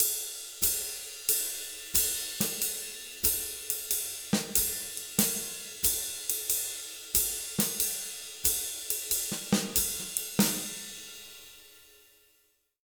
92SWING 05-L.wav